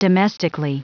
Prononciation du mot domestically en anglais (fichier audio)
Prononciation du mot : domestically